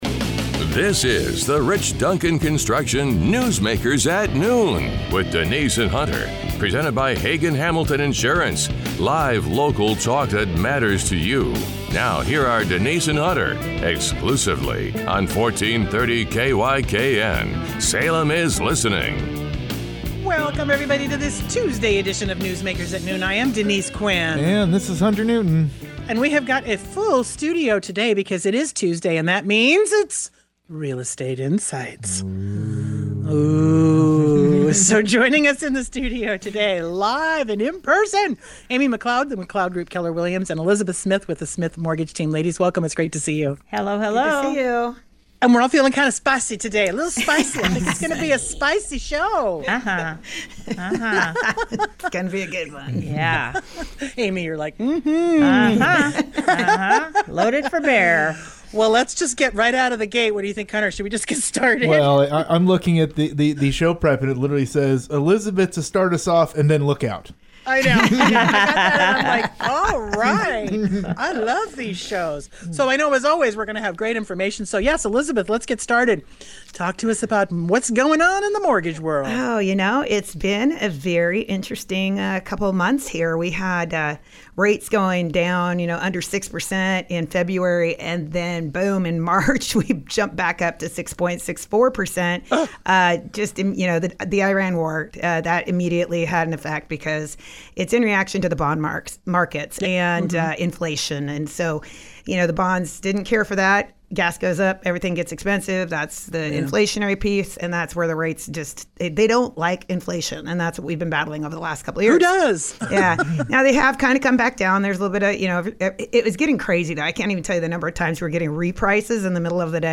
If you’re buying, selling, or just trying to understand what’s happening—this was a conversation grounded in reality, not hype.